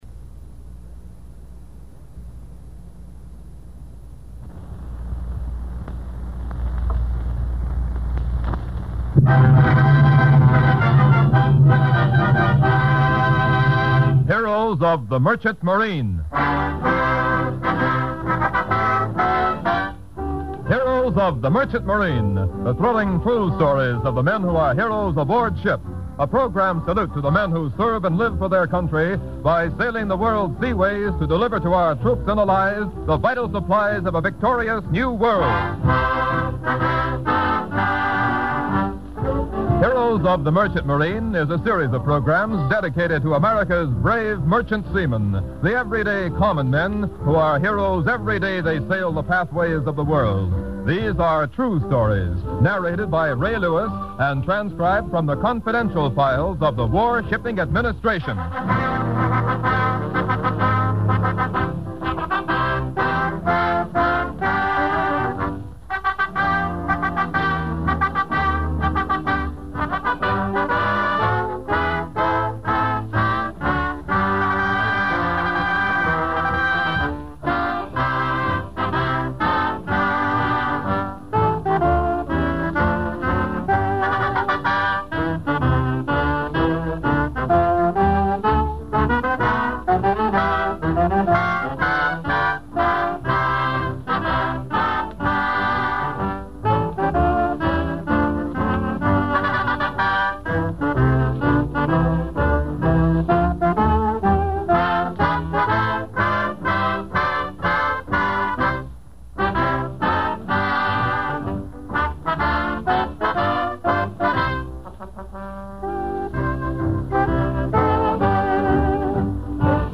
Radio Broadcast: The Heros of the Merchant Marine